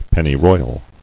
[pen·ny·roy·al]